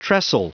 Prononciation du mot tressel en anglais (fichier audio)
Prononciation du mot : tressel